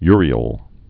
(yrē-əl)